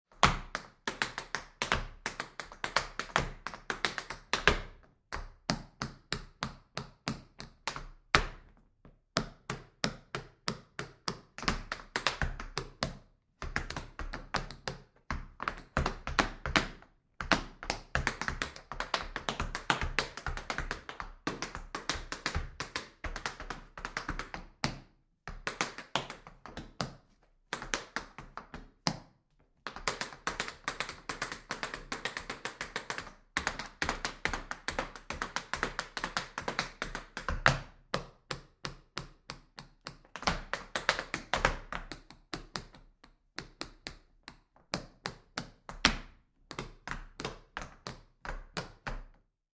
Звуки чечетки
На этой странице собраны яркие звуки чечетки в разных темпах и вариациях.
Чечётка - Такой вариант есть